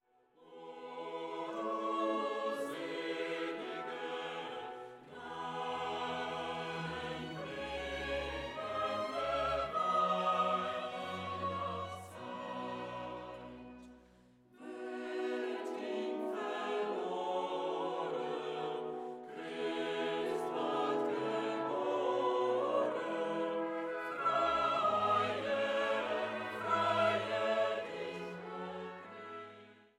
für Sopran, Bariton, Frauenchor und Orchester